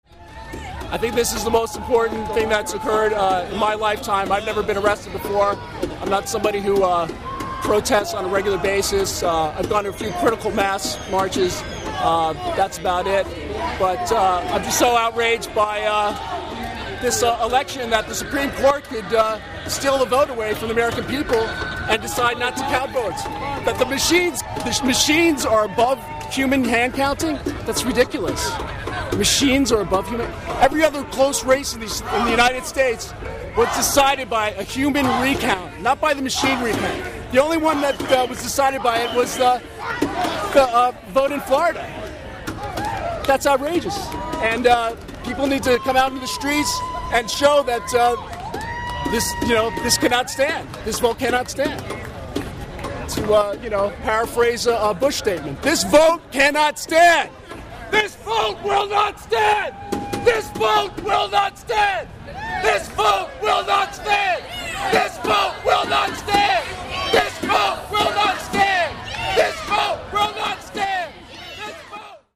Audio from man who stayed on the cable car turn-around at Powell & Market. (1:12)
This is a clip of what he had to say.
j20_guyoncablecarwheel.mp3